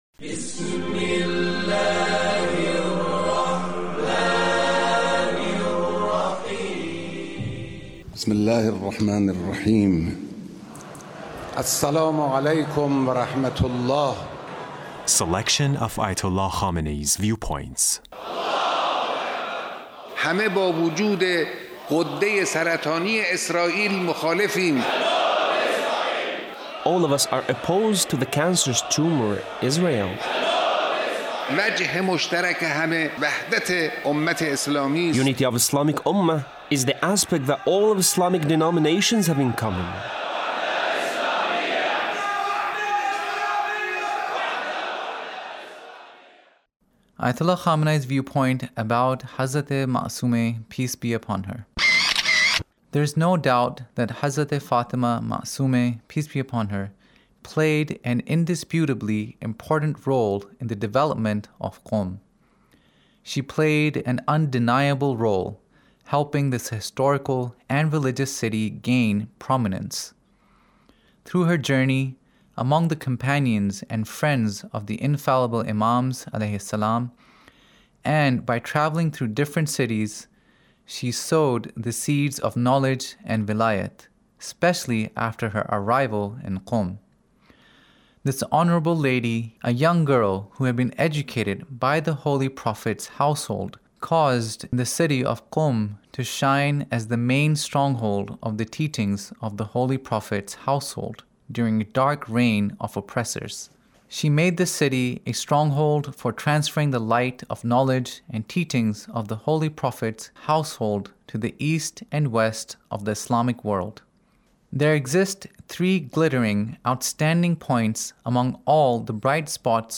Leader's Speech in a Meeting with the Three Branches of Government Repentance